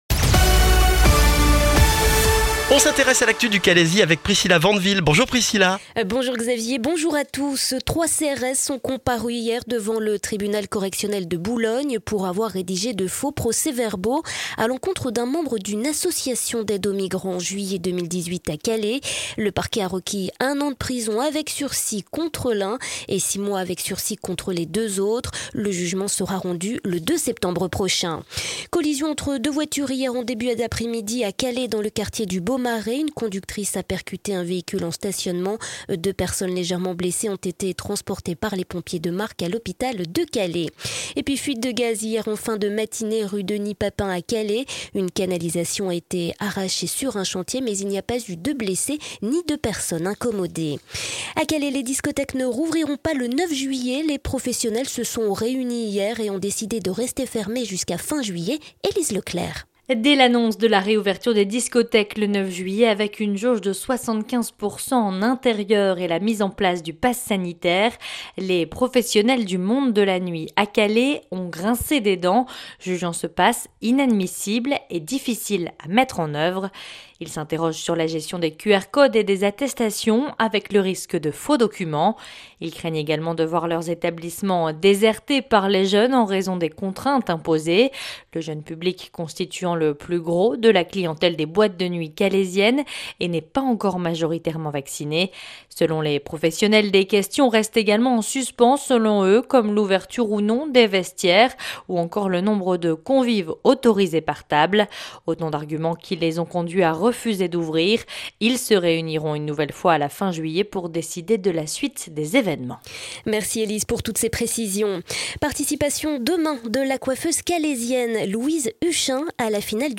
Le journal du mercredi 23 juin dans le Calaisis